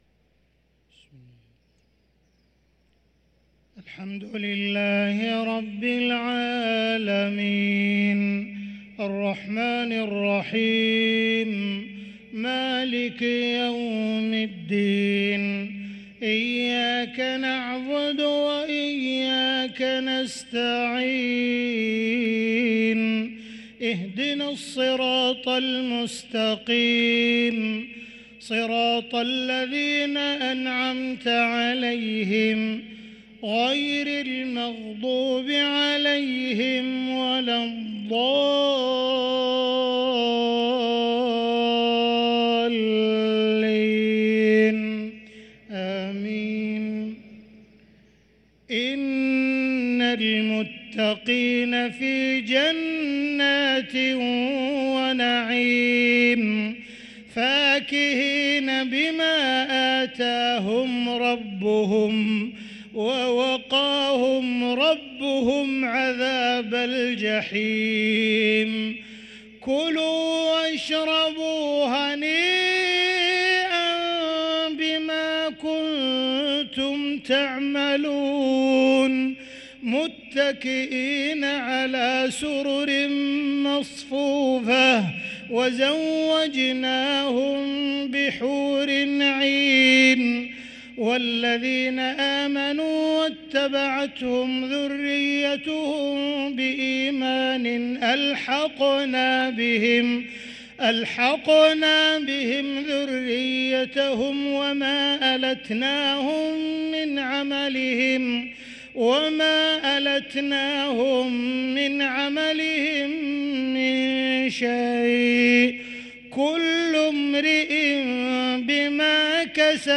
صلاة المغرب للقارئ عبدالرحمن السديس 21 رمضان 1444 هـ